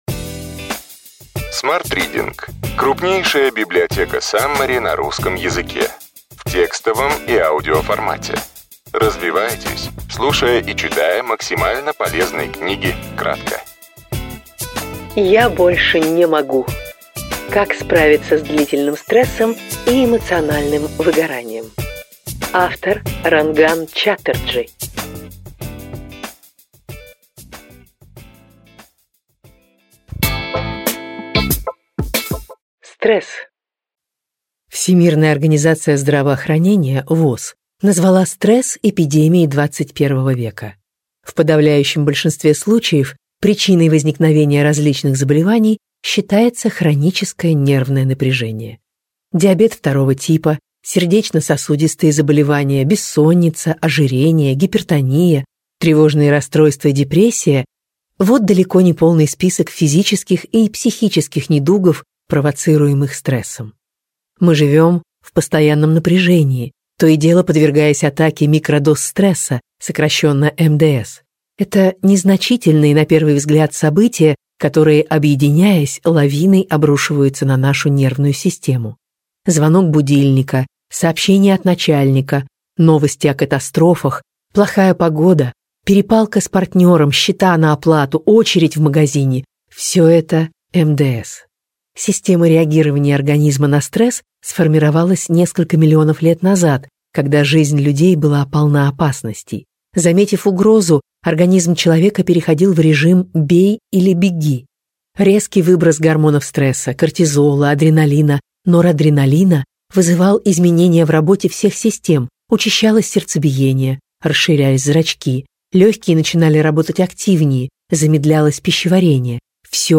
Аудиокнига Я больше не могу! Как справиться с длительным стрессом и эмоциональным выгоранием. Ранган Чаттерджи. Саммари | Библиотека аудиокниг